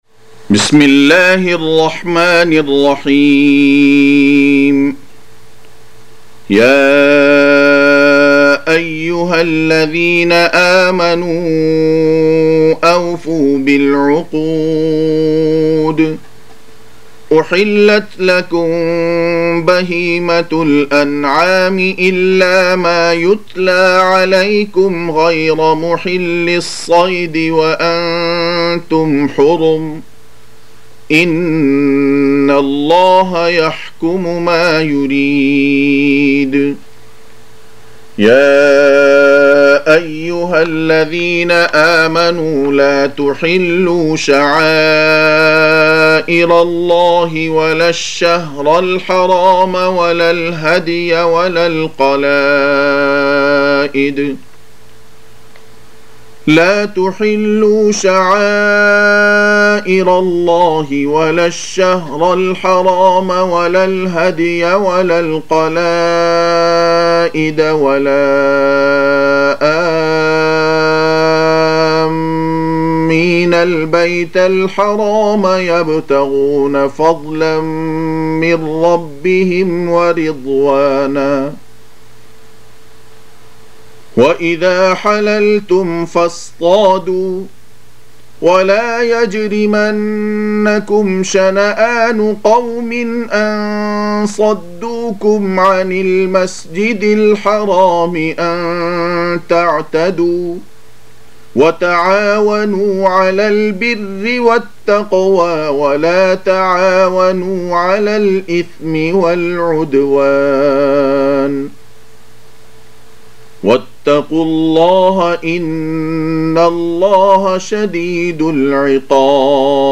5. Surah Al-M�'idah سورة المائدة Audio Quran Tarteel Recitation
Surah Sequence تتابع السورة Download Surah حمّل السورة Reciting Murattalah Audio for 5.